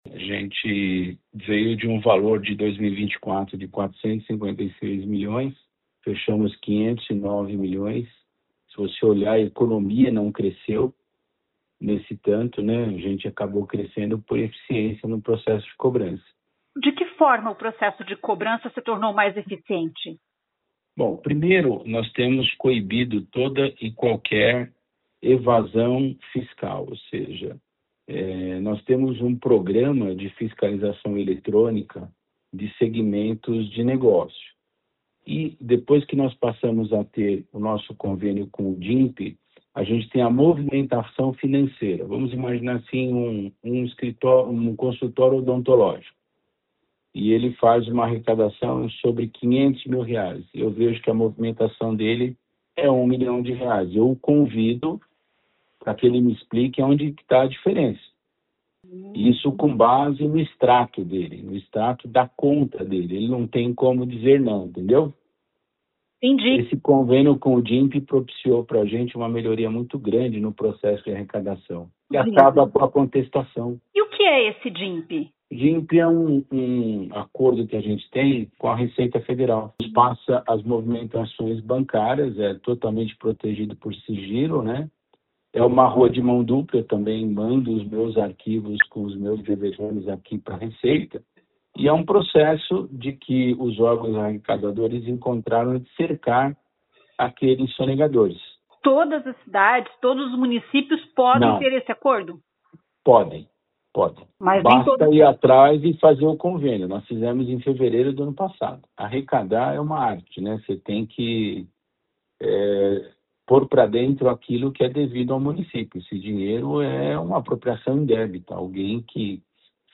Ouça o que diz o secretário de Fazenda Carlos Augusto Ferreira.